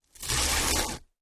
ho_fleshtear_04_hpx
Various parts of human flesh being ripped and torn. Mutilation, Body Dismemberment, Gore Tear, Flesh